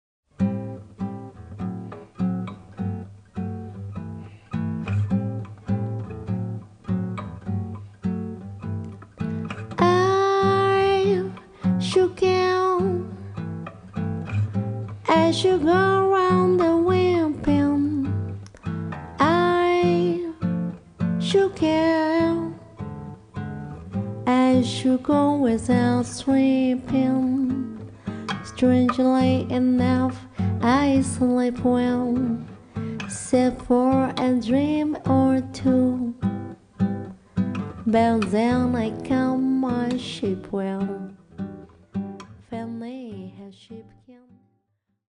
Recorded on Dec. 22nd and 23rd, 2024 at Studio Dede, Tokyo